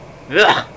Ack Sound Effect